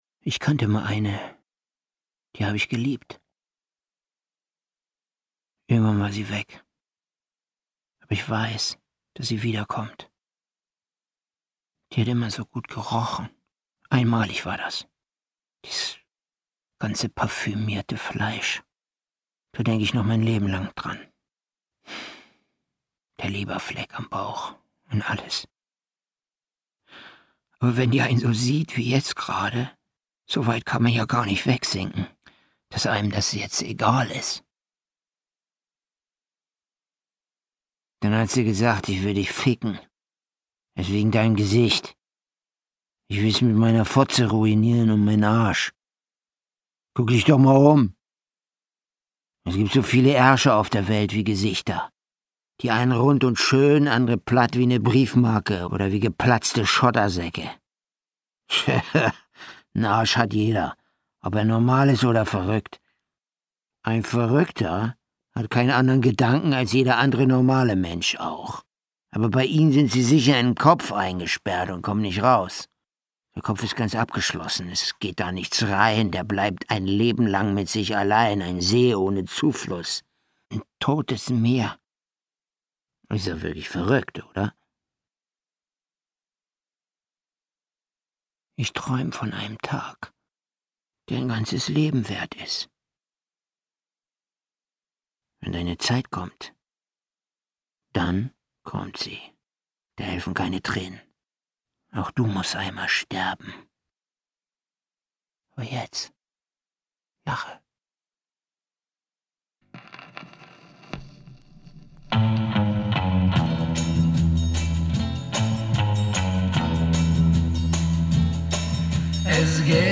Hörspiel mit Lars Rudolph, Ulrike Krumbiegel u.v.a. (1 CD)
Lars Rudolph, Sebastian Rudolph, Ulrike Krumbiegel (Sprecher)